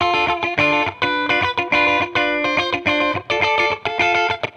Index of /musicradar/sampled-funk-soul-samples/105bpm/Guitar
SSF_TeleGuitarProc2_105D.wav